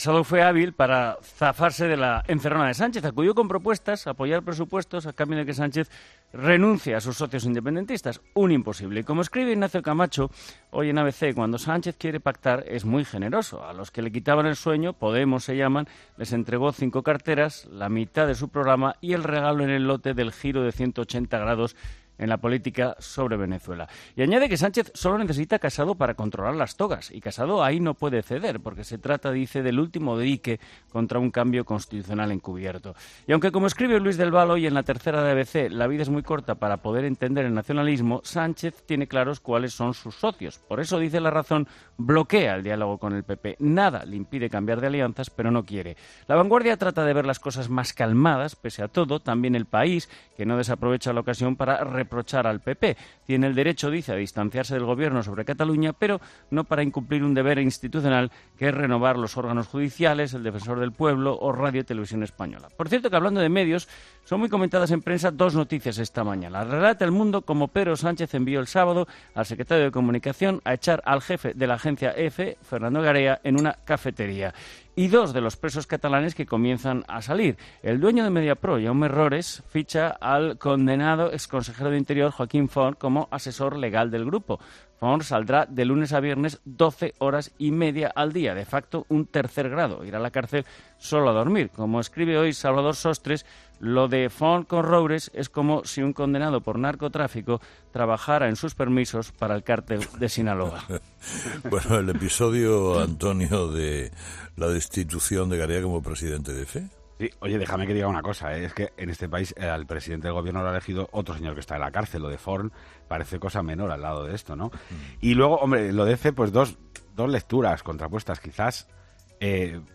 La tertulia de 'Herrera en COPE' analiza el encuentro de Sánchez con Casado en Moncloa